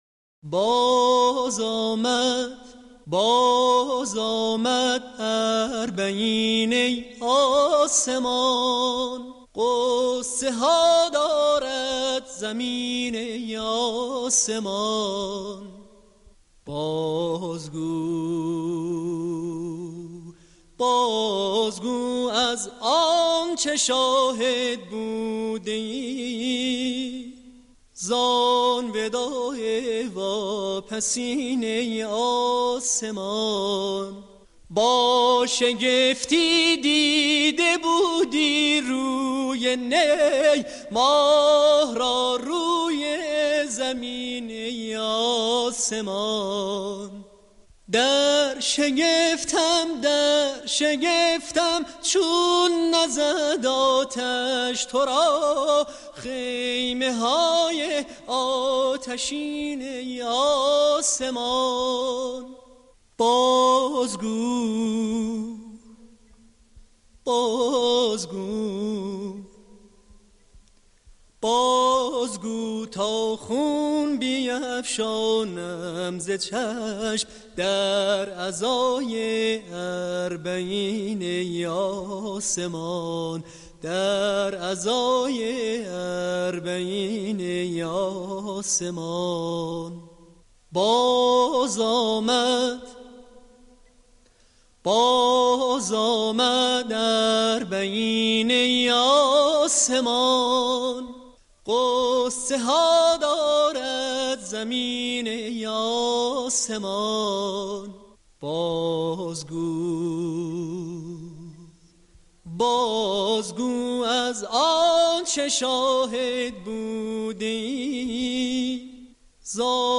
همخوانی زیبای باز آمد اربعین ای آسمان به همراه فایل صوتی